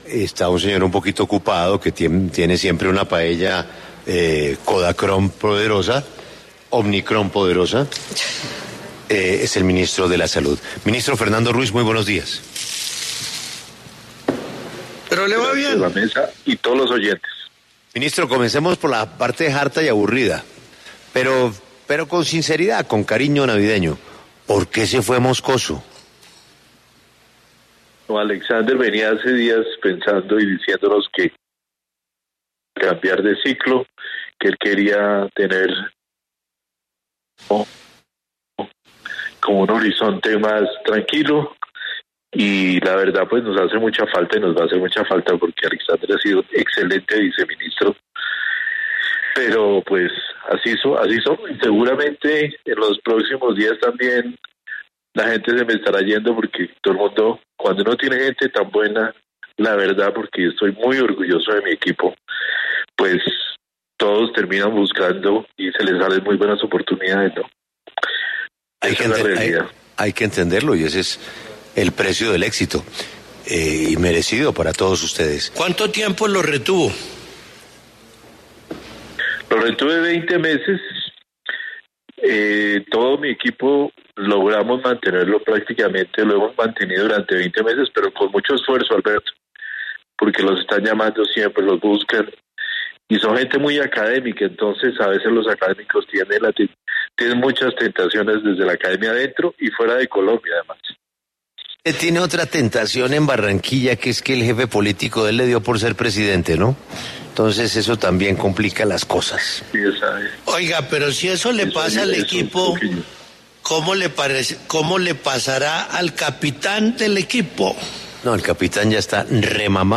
En diálogo con La W, el ministro de Salud, Fernando Ruiz, se refirió al futuro de una eventual producción de vacunas contra el COVID-19 en el país.